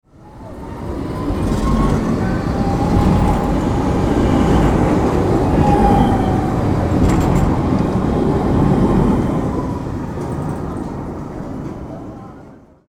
Tram Passing Through The Street Sound Effect
Description: Tram passing through the street sound effect. The streetcar accelerates and moves swiftly through the city environment. Street sounds.
Tram-passing-through-the-street-sound-effect.mp3